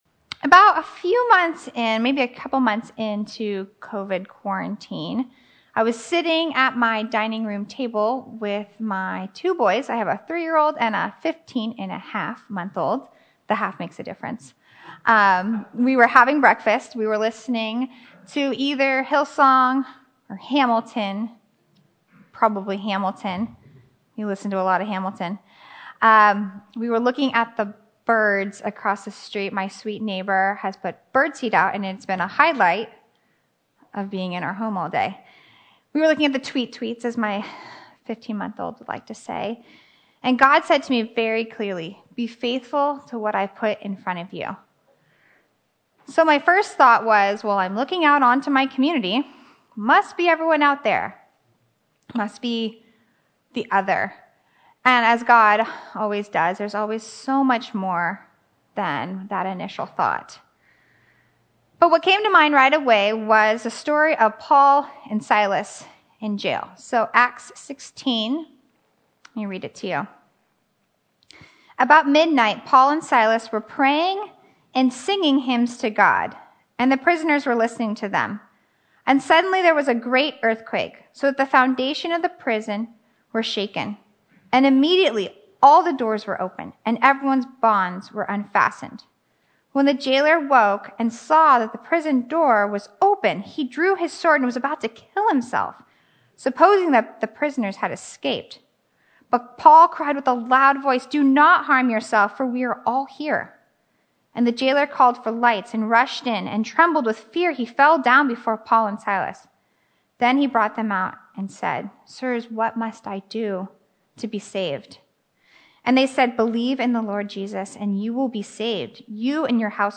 Sermons from Quarantine: Be Faithful